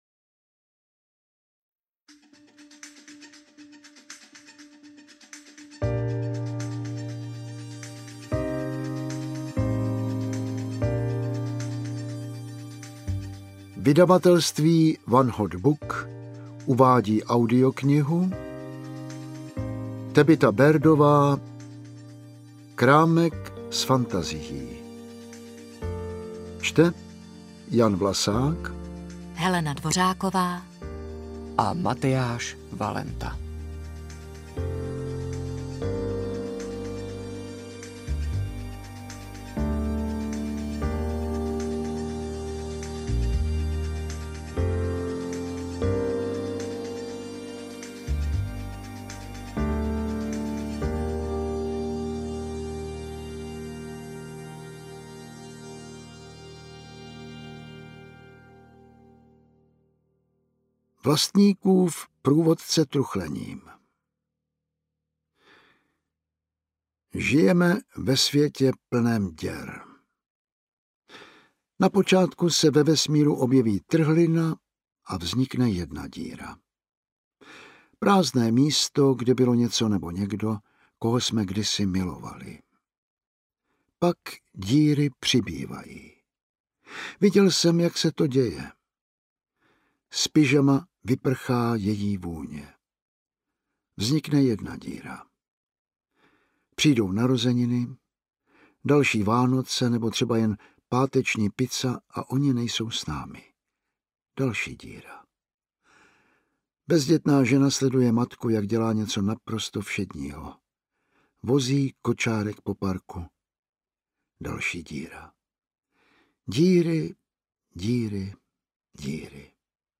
Krámek s fantazií audiokniha
Ukázka z knihy
kramek-s-fantazii-audiokniha